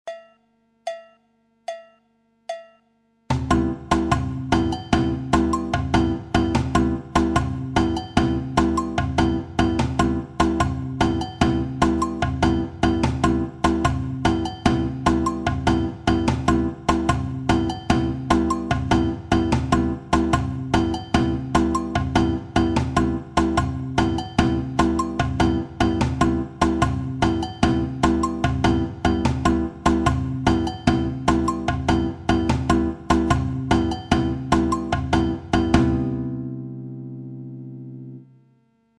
La samba figure 1
section rythmique avec la guitare sur un accord de C7M. guitare samba 1 seule avec le tambourim, le surdo, la clave et l'agogo.
Phrasé samba à la guitare